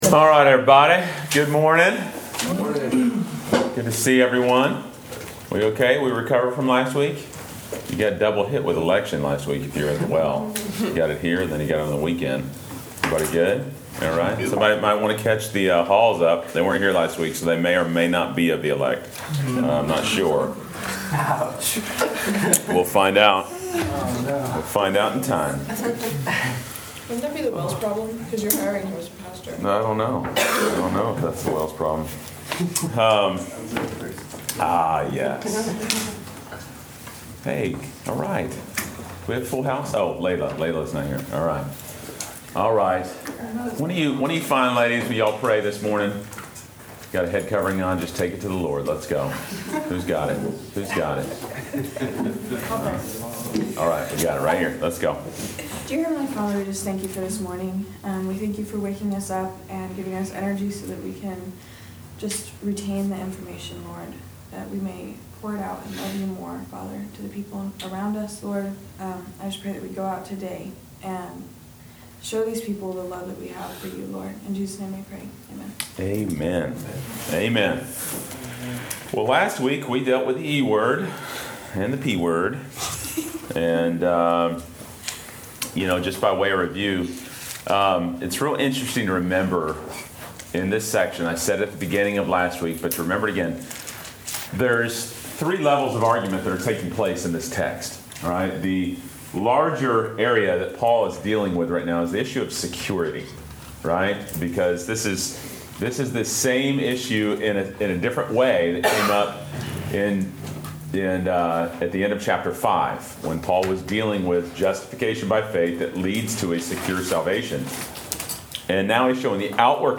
Class Session Audio February 19